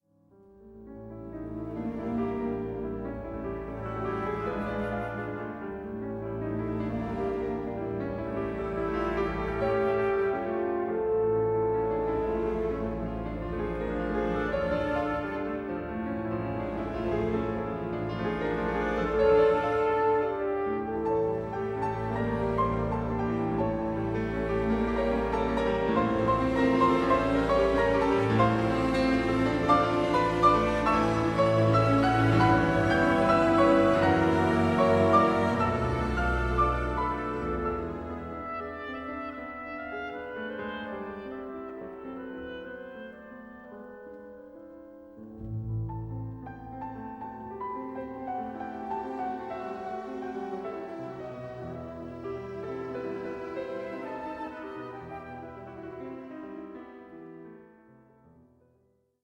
DIGITALLY REMASTERED